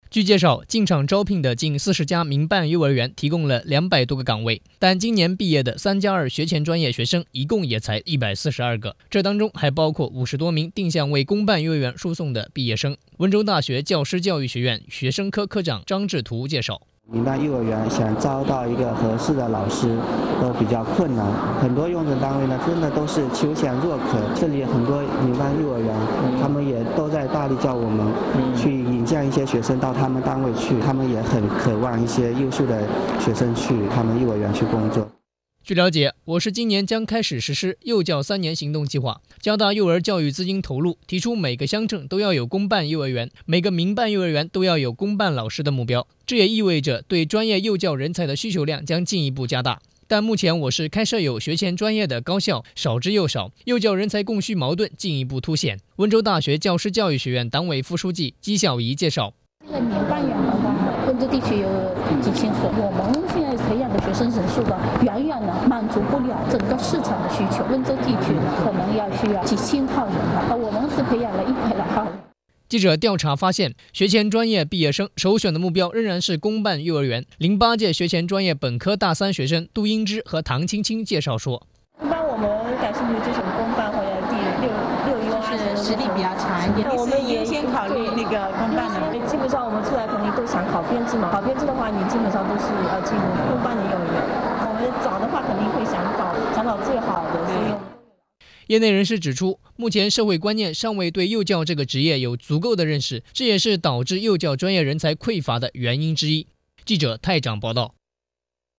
播放栏目：温州新闻联播